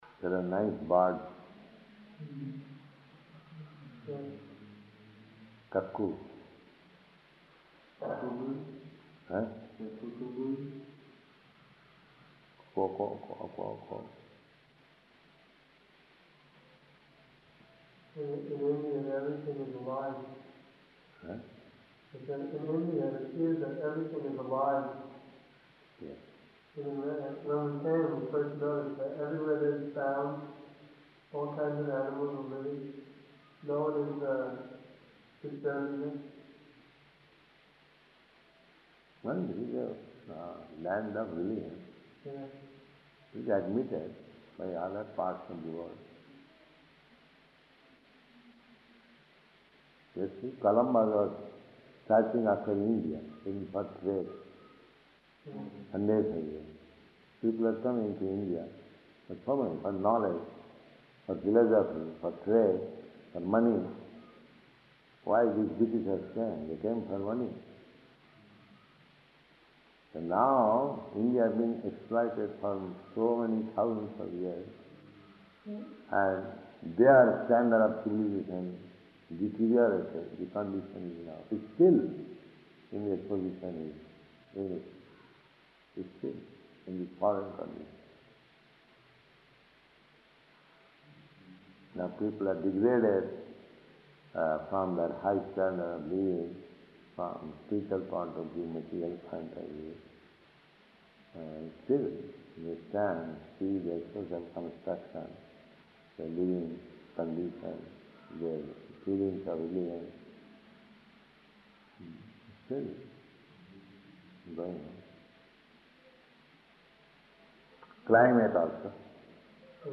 Room Conversation
Room Conversation --:-- --:-- Type: Conversation Dated: November 6th 1970 Location: Bombay Audio file: 701106R1-BOMBAY.mp3 Prabhupāda: There are nice birds.